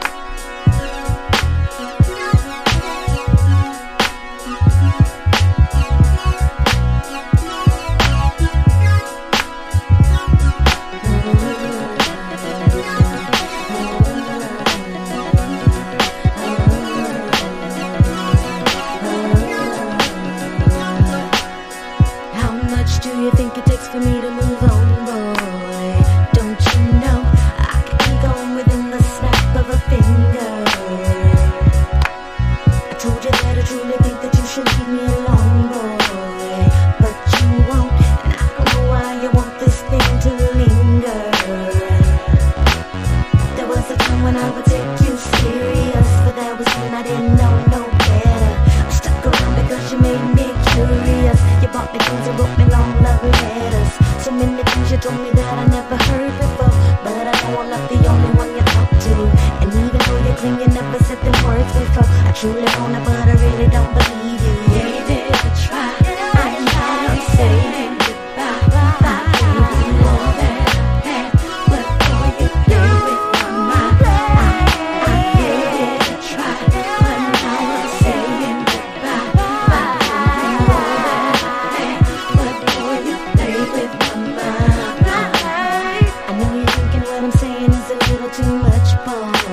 クロスオーヴァーな魅力に溢れたエレクトロ〜ビート・ダウンな2017年製フューチャーR&B！